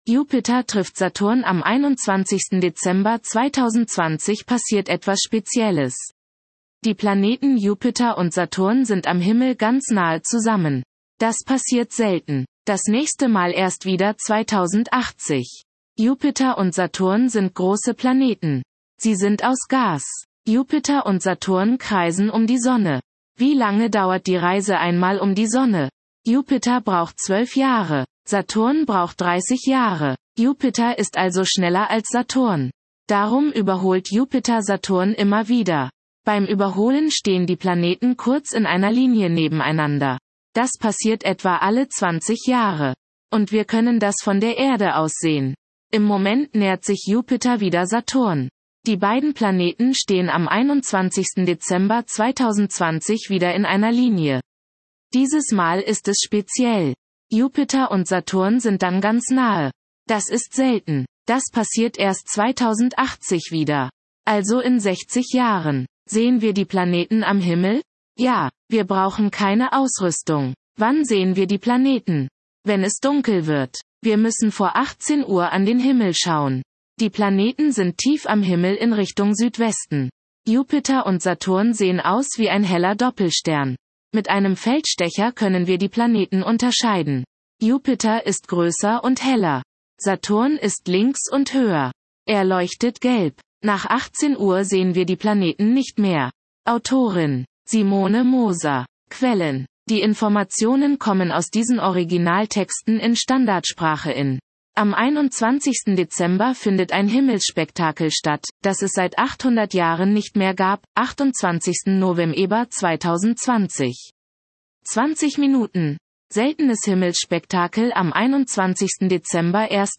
Vorlesen